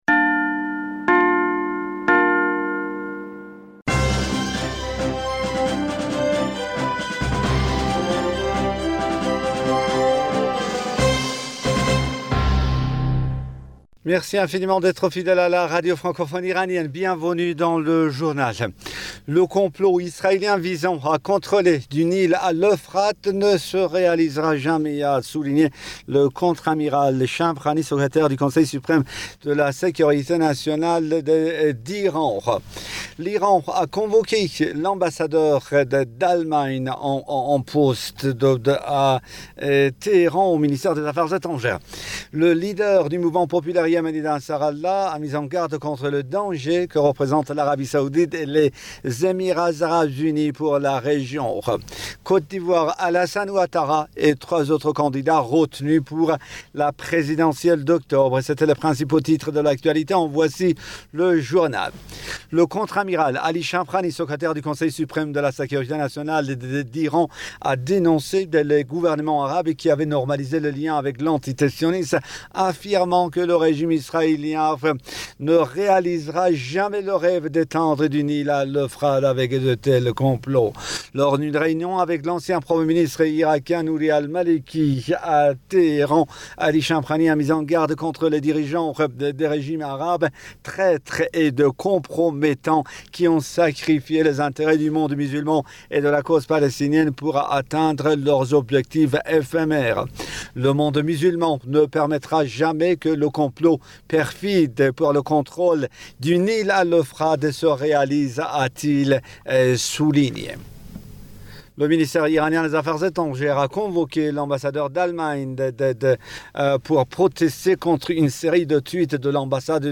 Bulletin d'information du 15 septembre 2020